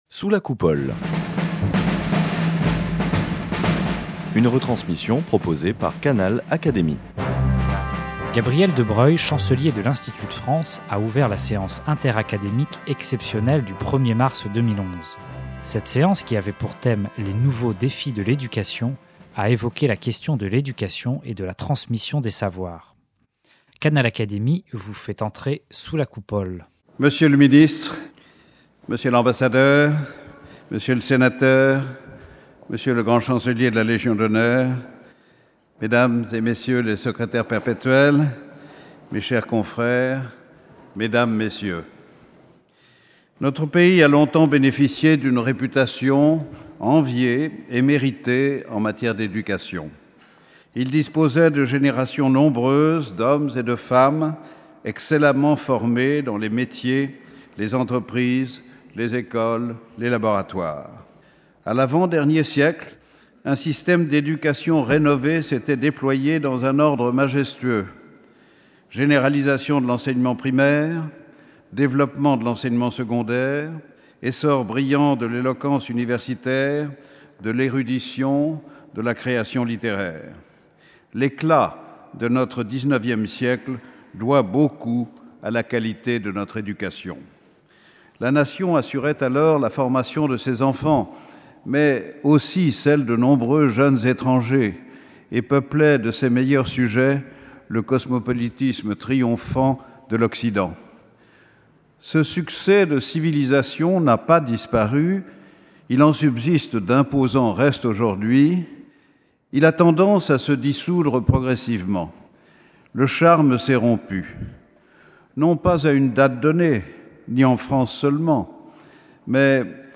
Les académiciens se penchent sur le thème de l’éducation. Lors de la séance interacadémique exceptionnelle du 1er mars 2011, Xavier Darcos, Secrétaire perpétuel de l’Académie des sciences morales et politiques, a prononcé un discours sur le thème "École et Nation".
Sous la coupole de l’Institut de France et en présence du Chancelier Gabriel de Broglie qui introduisit cette séance, l’ancien Ministre de l’Éducation nationale a traité la question de l’éducation et de la transmission des savoirs.